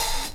Havoc OpenHiHat 1.wav